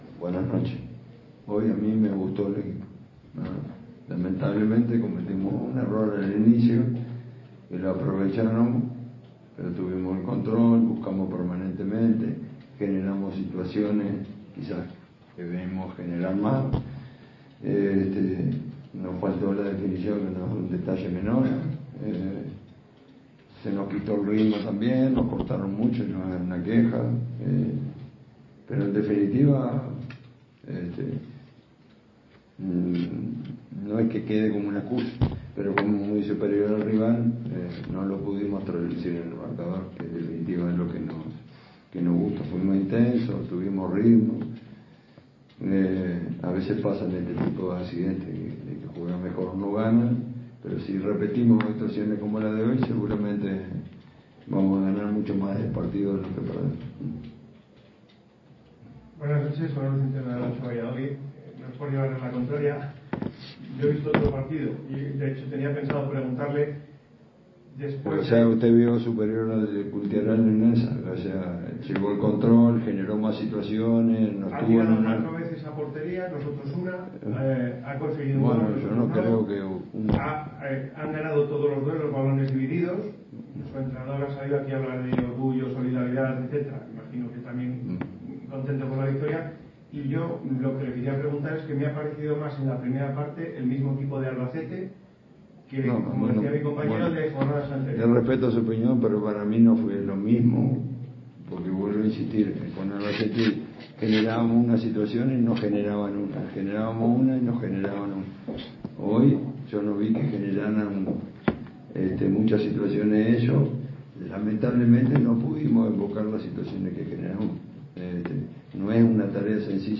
Ruedas de prensa
aquí la rueda de prensa completa